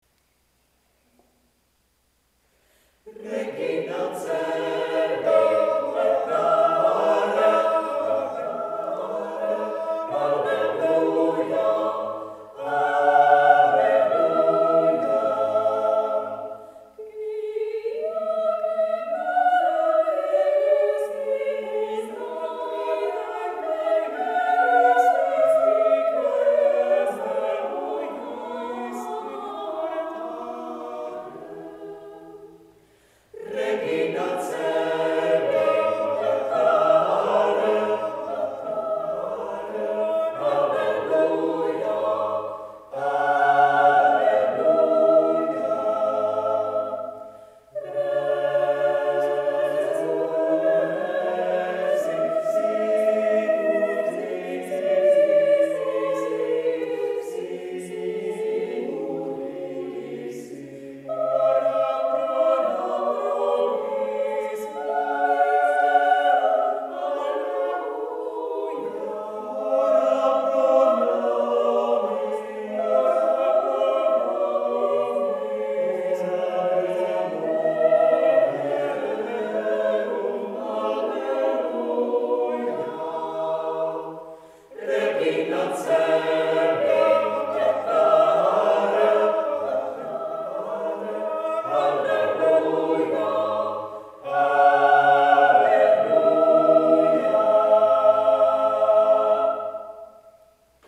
Koncert v Dýšině, 1. 5. 2010 (části zpívané Vox Imperfecta)